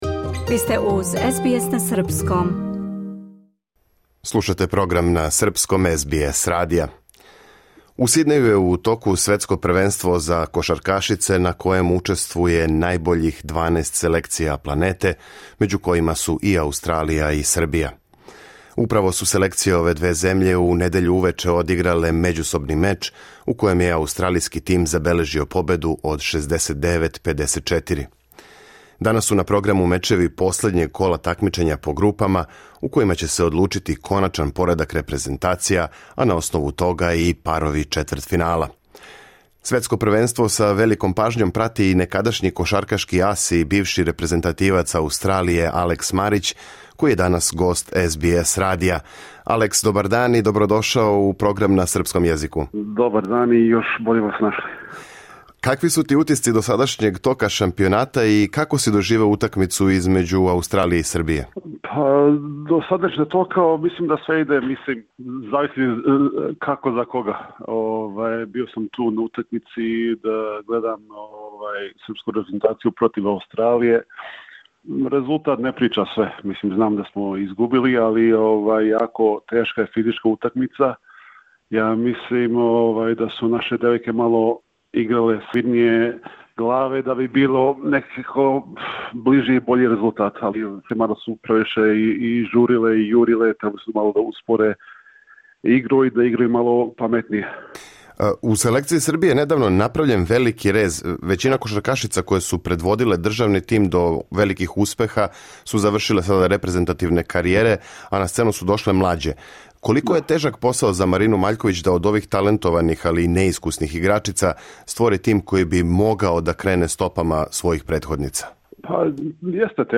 Бивши кошаркашки ас Алекс Марић сматра да српска женска репрезентација у будућности може да настави путем претходне генерације која је освајала медаље на великим такмичењима. У разговору за СБС радио, говори и о моментима који су обележили његову играчку каријеру, о тренерском раду с младим играчима, великом потенцијалу кошаркаша Кингса Дејана Васиљевића и предстојећем Купу Драже Михаиловића у Вулонгонгу